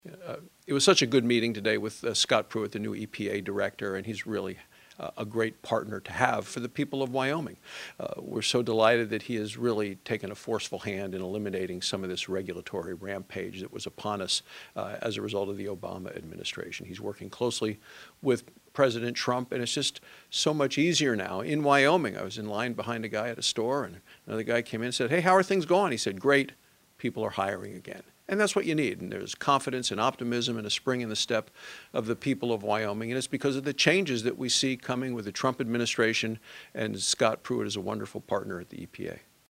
May 3, 2017 - EPA Administrator Scott Pruitt's meeting with Senator John Barrasso, Washington, DC.
Speaker: Sen. John Barasso